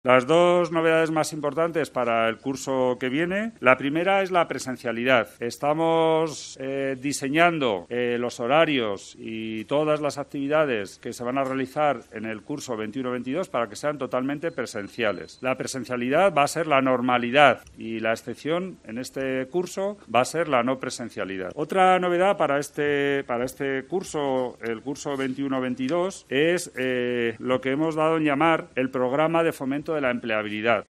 El rector de la UR, Juan Carlos Ayala, anuncia que el próximo curso 2021-2022 se desarrollará con una presencialidad del 100% de las clases y actividades formativas